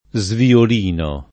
sviolino [ @ viol & no ]